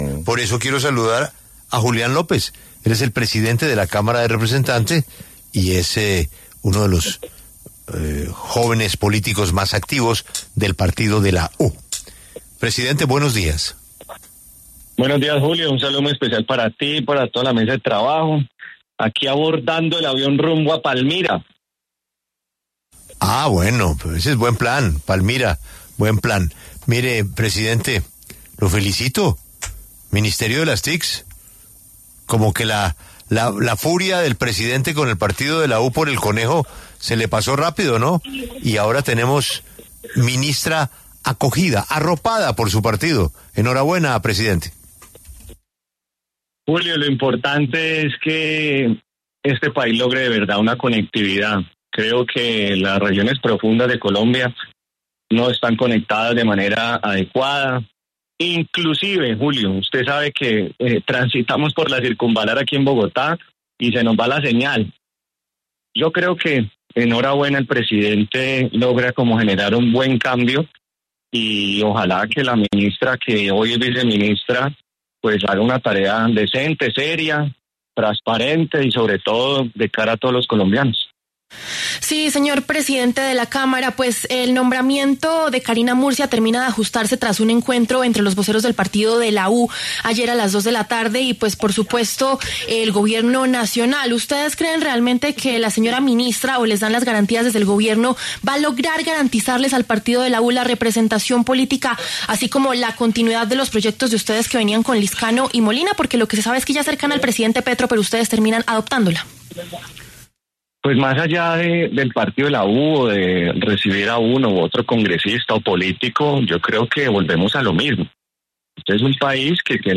El presidente de la Cámara, Julián López, pasó por los micrófonos de La W, donde respondió por el nombramiento de Carina Murcia como nueva ministra de las TIC, y por el informe de la aprobación de la reforma pensional, para evitar que se caiga por inconstitucionalidad.